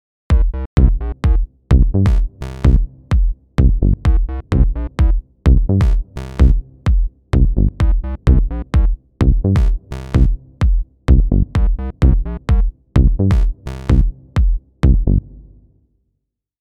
Bomp, Bomp, Bomp goes the baseline on this one.